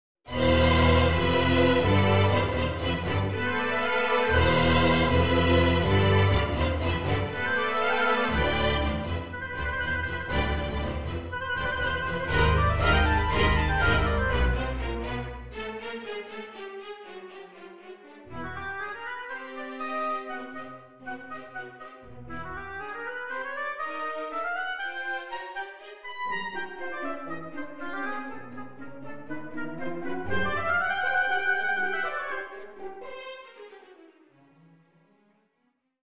I Allegro gusto
Oboe, Violin I, Violin II, Viola, Cello and Double Bass